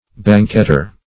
Banquetter \Ban"quet*ter\, n. One who banquets; one who feasts or makes feasts.